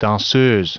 Prononciation du mot danseuse en anglais (fichier audio)
Prononciation du mot : danseuse